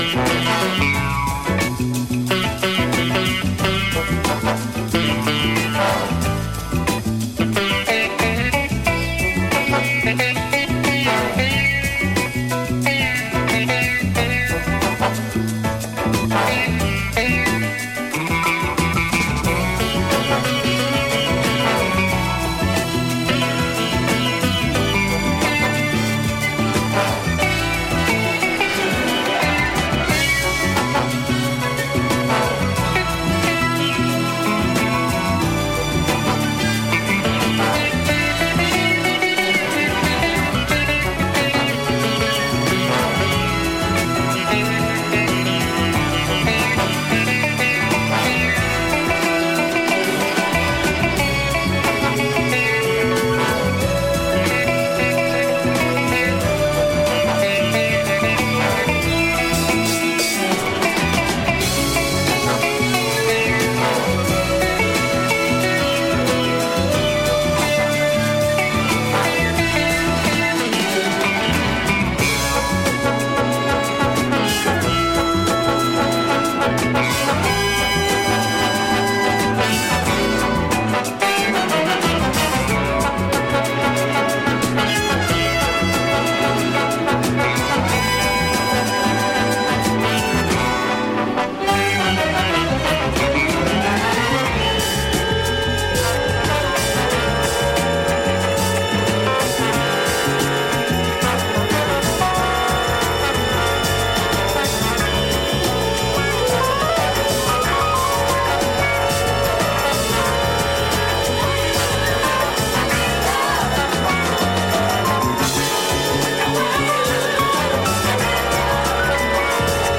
Solid funky soul soundtrack
Soul Soundtrack